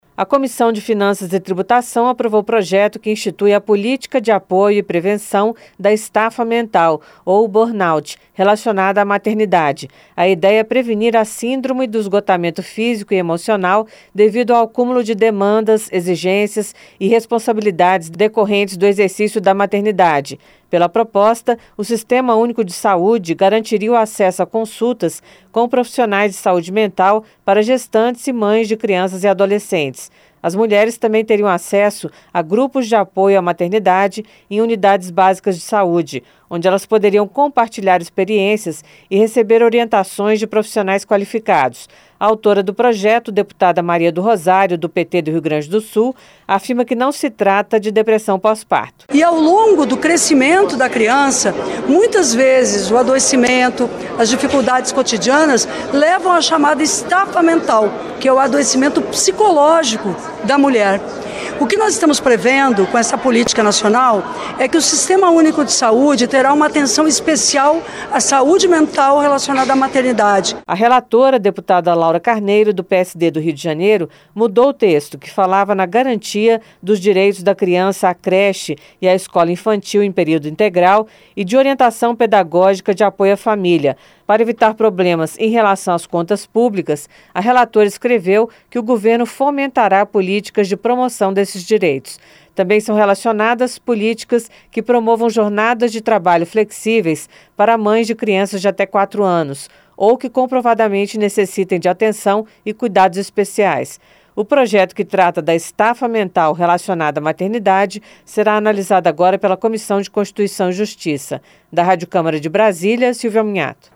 PROJETO APROVADO PREVÊ ATENÇÃO ESPECIAL DA SAÚDE PÚBLICA COM A SOBRECARGA MENTAL DAS MÃES DE CRIANÇAS E ADOLESCENTES. A REPORTAGEM